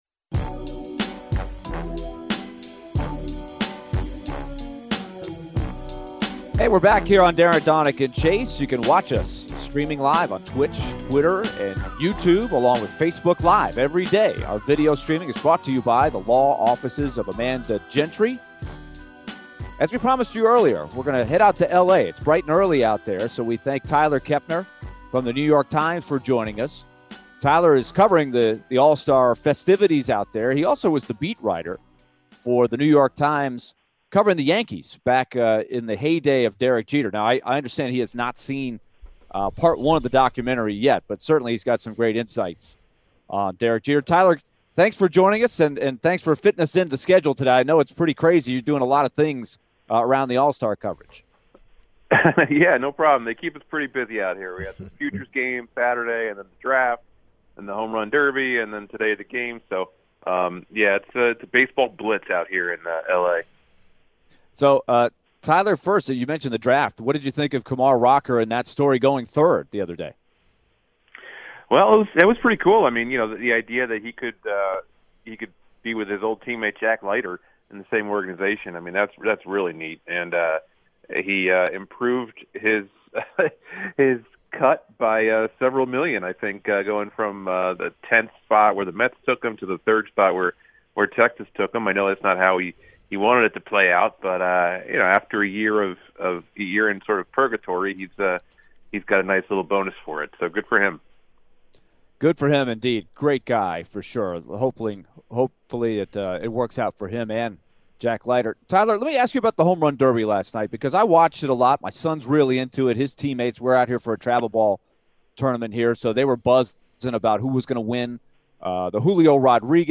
Tyler Kepner Full Interview (07-19-22)